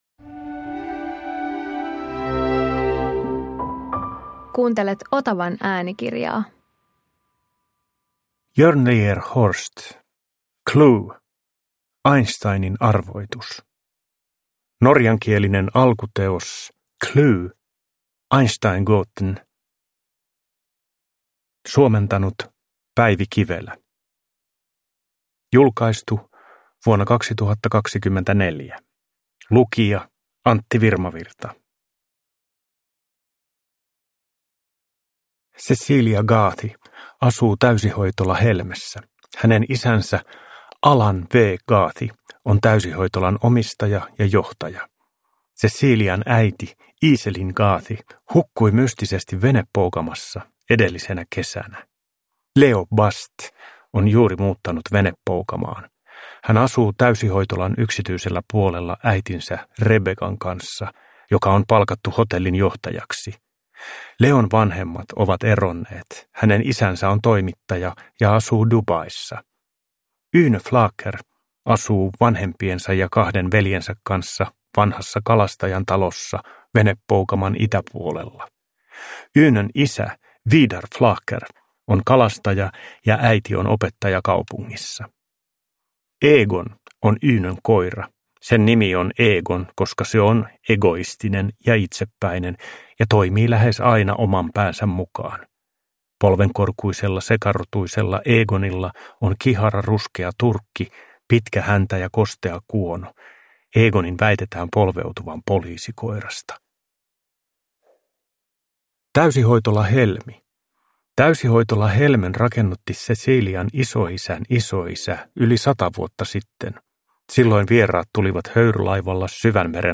CLUE – Einsteinin arvoitus – Ljudbok